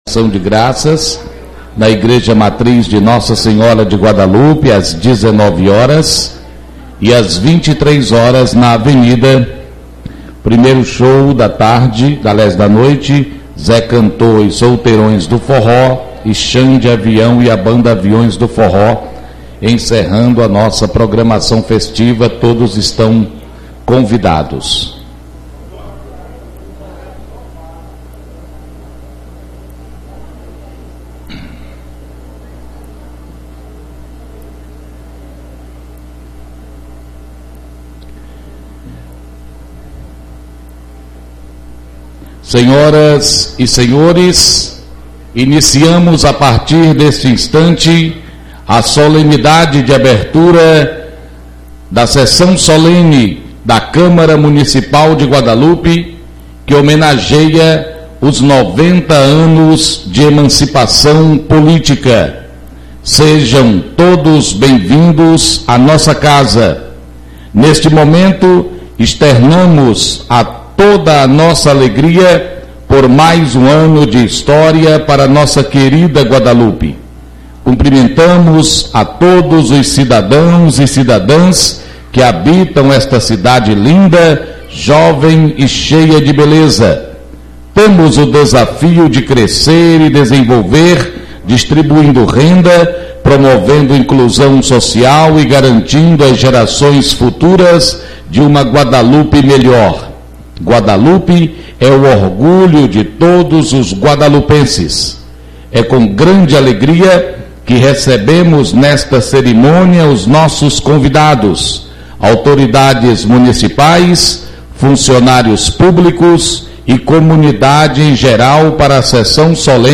21 - Sessao Ordinaria 25.08.2019